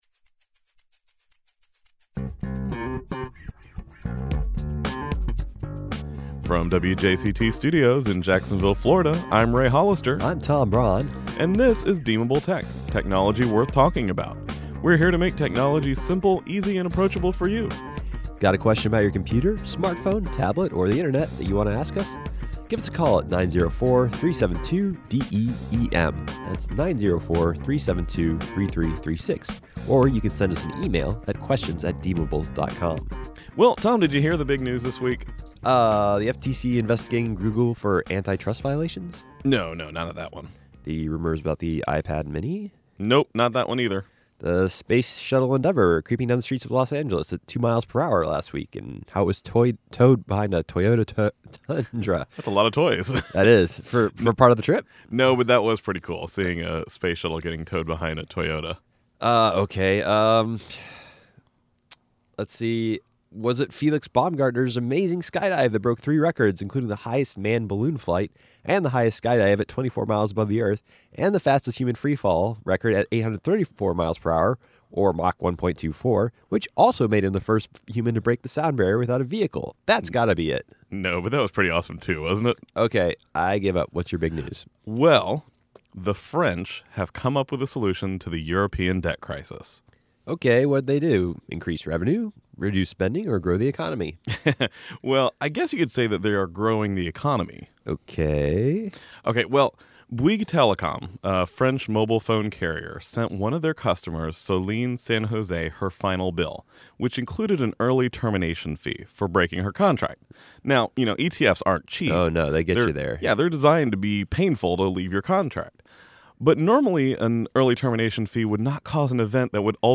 This week WJCT is doing their annual fall pledge drive, so we didn’t have much time in the studio, but we were able to get one question squeezed in.